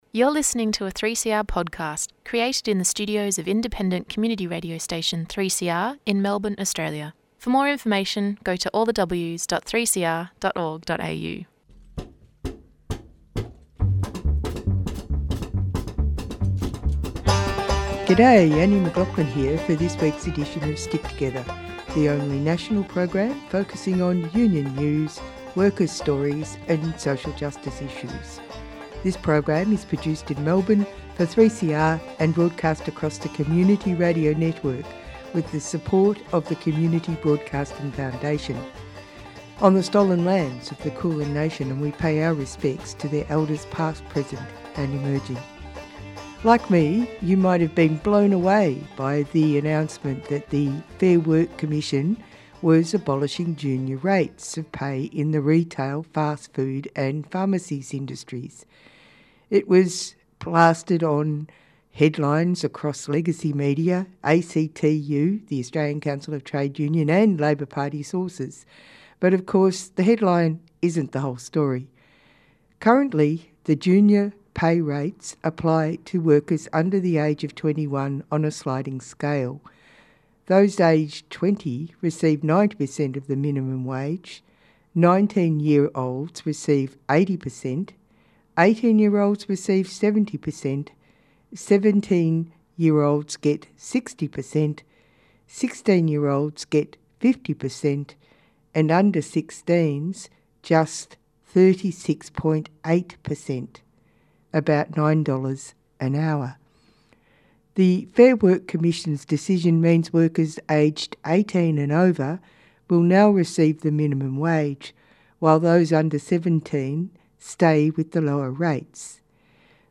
Tweet Stick Together Wednesday 8:30am to 9:00am Australia's only national radio show focusing on industrial, social and workplace issues.